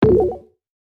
Futuristic Alert.wav